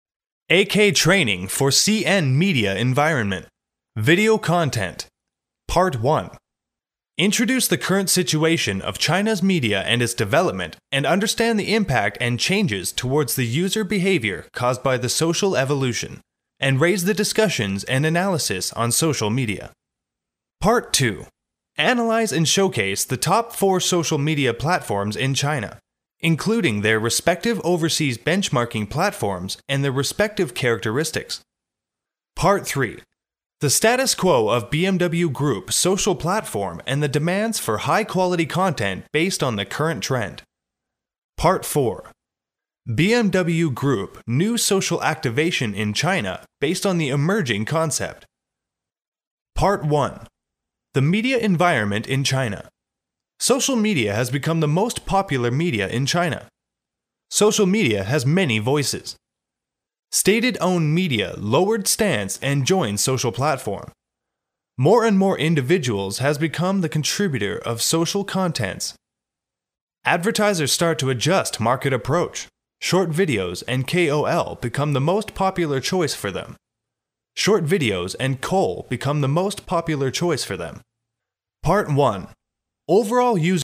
男英4号（外籍）